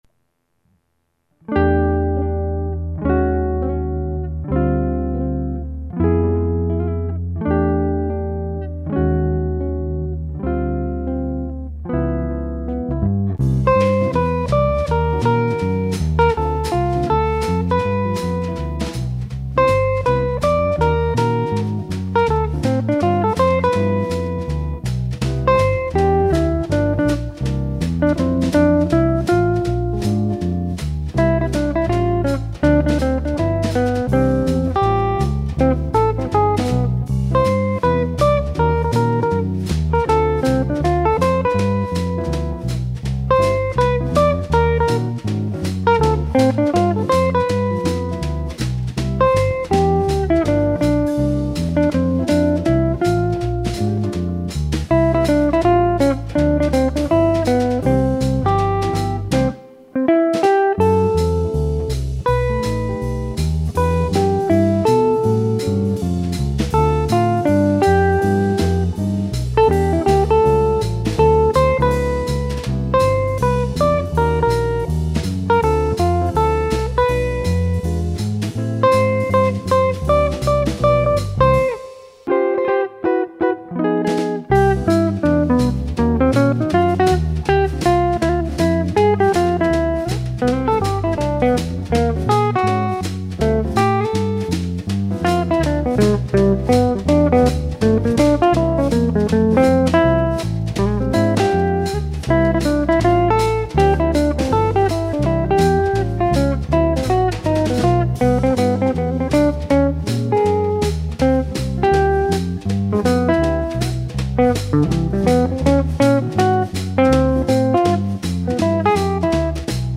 Chitarre e Basso
Pianoforte e Vibrafono
Batteria